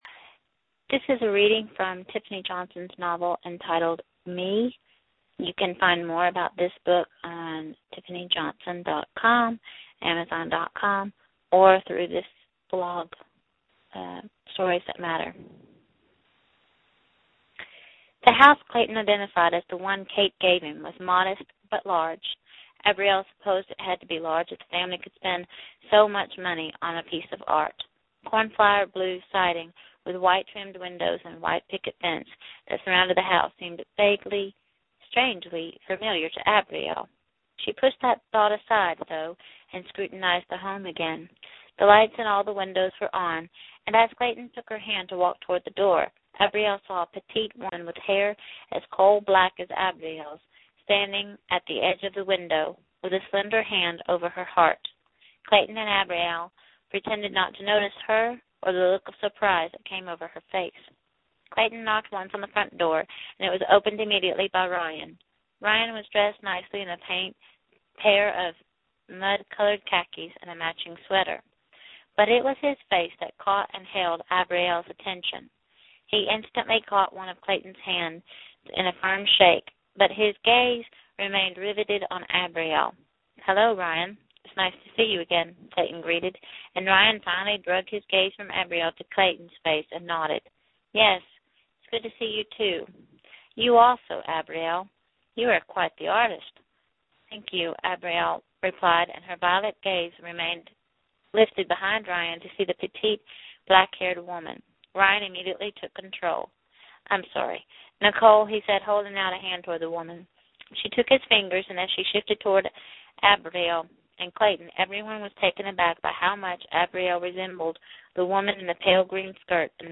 Reading of Me